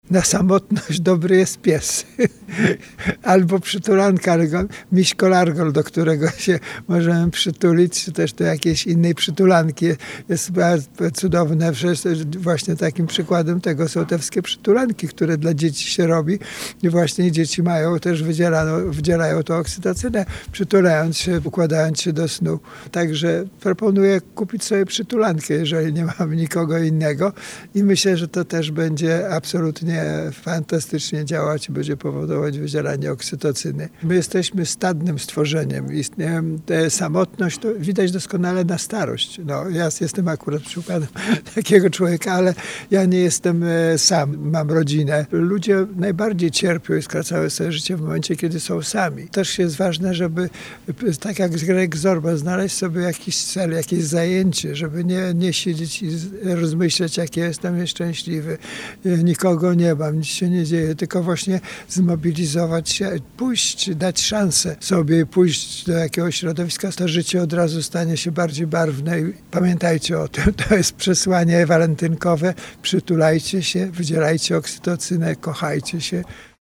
Cała rozmowa w piątek 14 lutego po godz. 8:10 w audycji „Poranny Gość”.